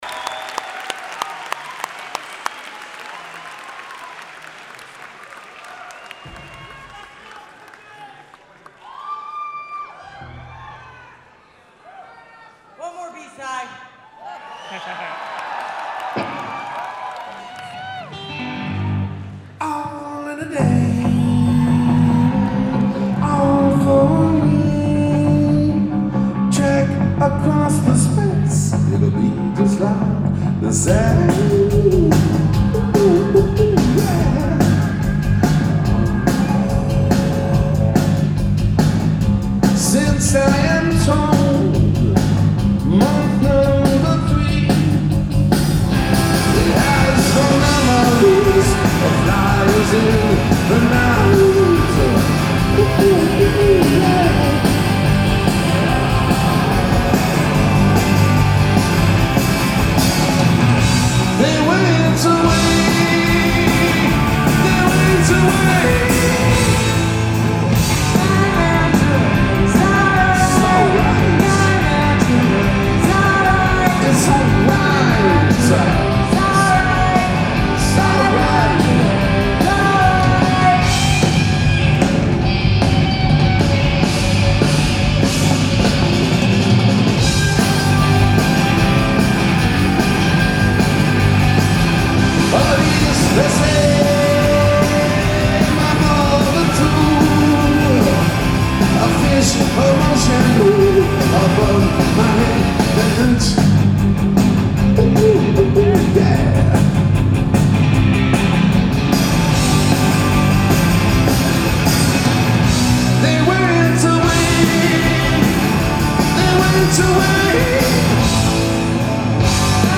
Live at the Wang Theatre / Citi Center
Audience recording
Mics = DPA 4061 > Custom BB > R09HR @ 24/96
Location = Front Row Mezzanine